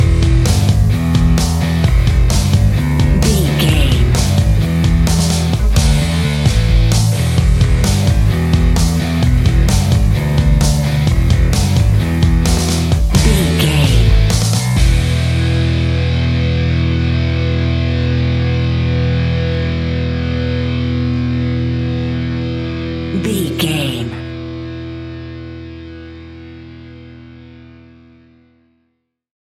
Epic / Action
Fast paced
Aeolian/Minor
hard rock
blues rock
distortion
rock guitars
Rock Bass
Rock Drums
heavy drums
distorted guitars
hammond organ